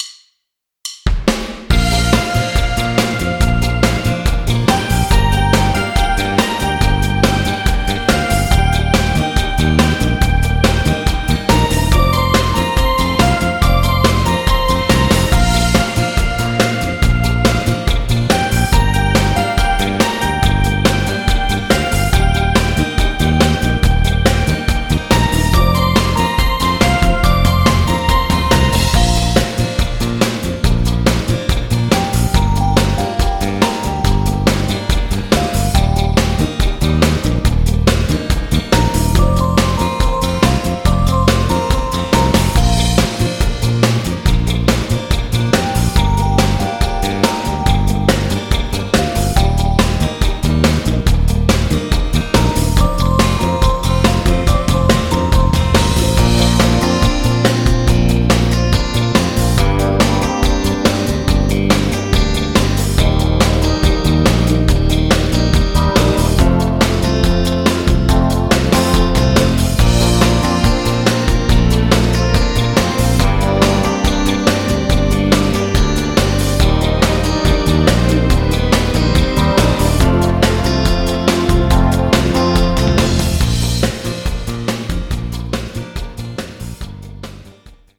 Playback, Instrumental, Karaoke